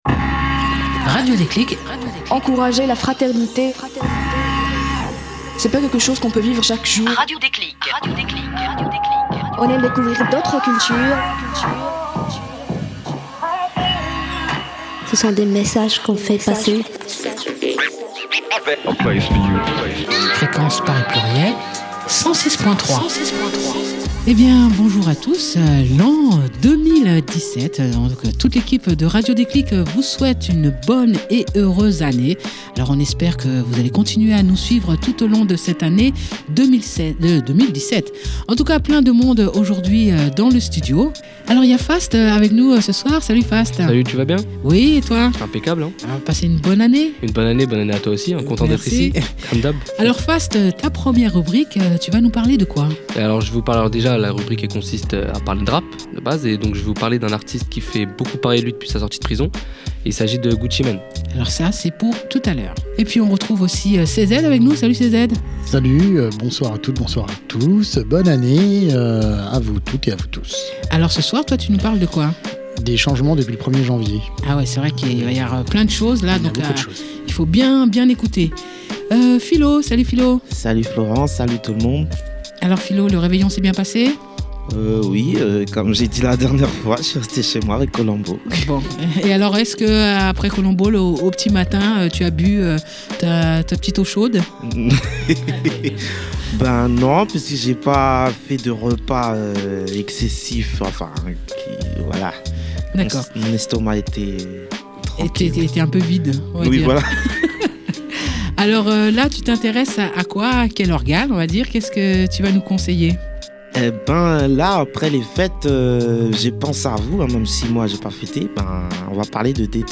Emission diffusée le 6 janvier sur les ondes de FPP 106.3fm